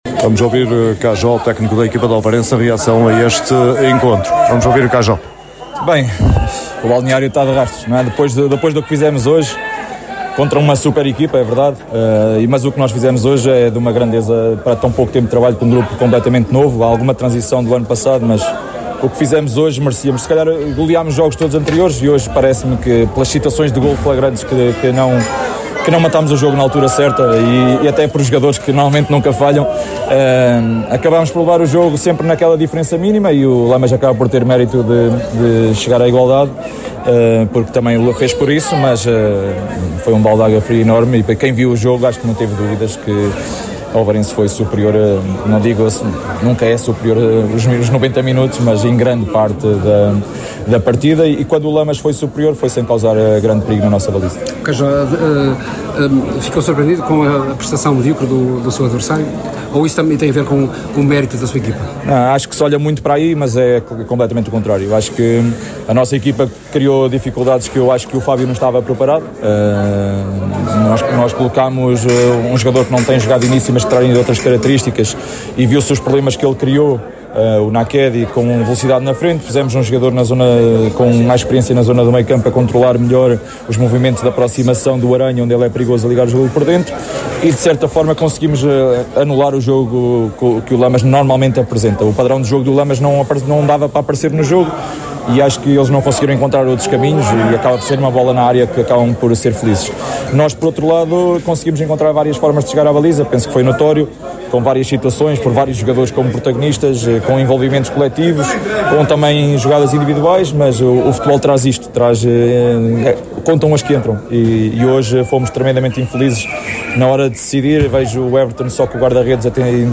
No final do jogo, a Sintonia procurou ouvir os intervenientes de ambas as equipas.